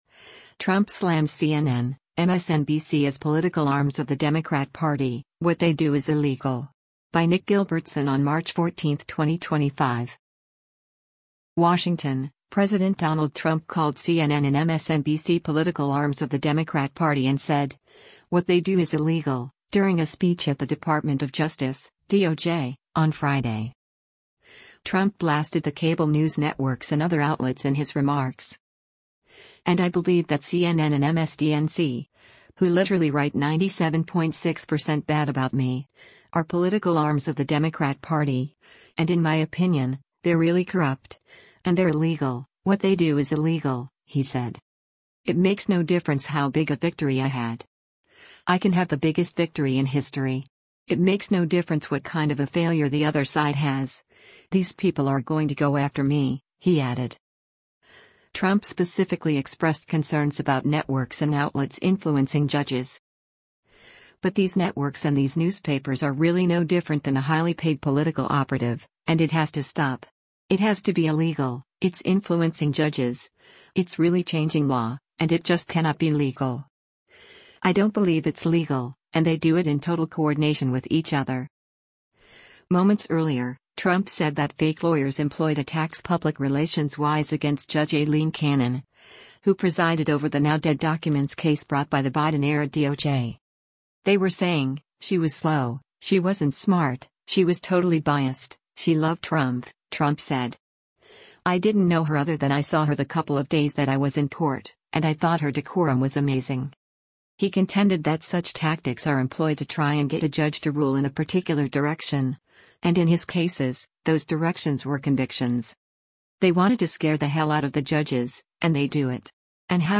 WASHINGTON — President Donald Trump called CNN and MSNBC “political arms of the Democrat Party” and said, “what they do is illegal,” during a speech at the Department of Justice (DOJ) on Friday.